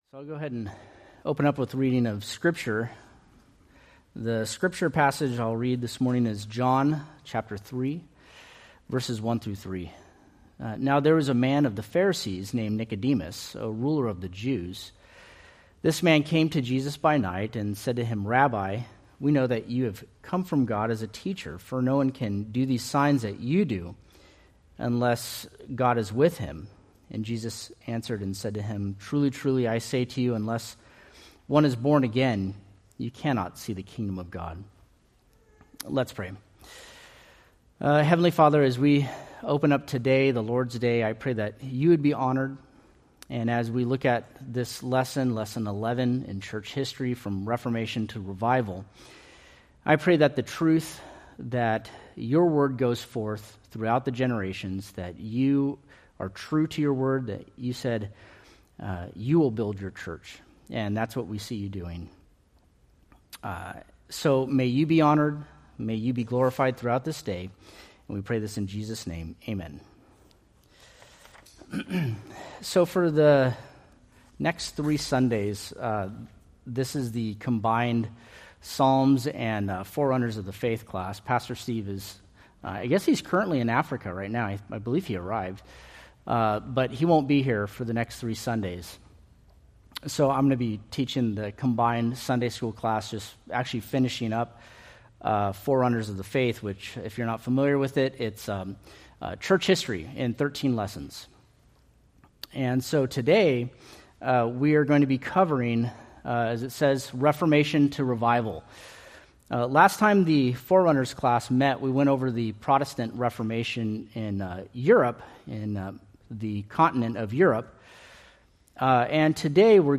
Date: May 18, 2025 Series: Forerunners of the Faith Grouping: Sunday School (Adult) More: Download MP3